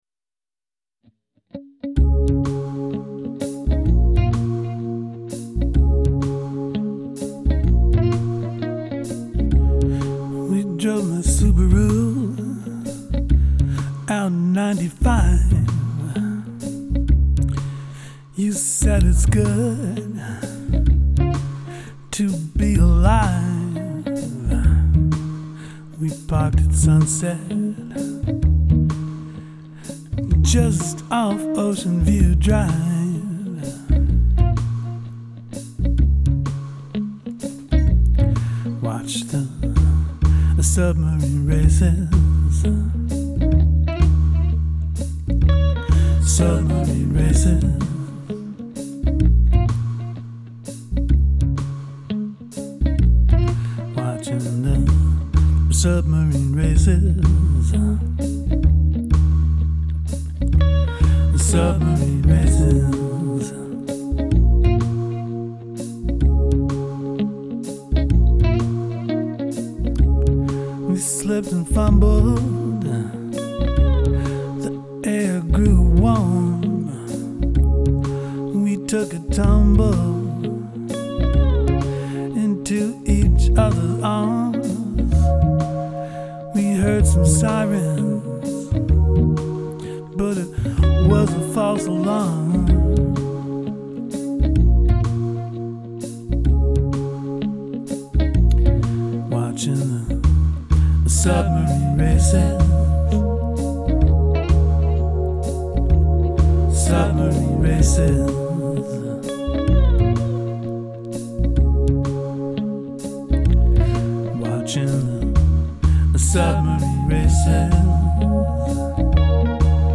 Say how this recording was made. [ ruff basics - not for sale or distribution ]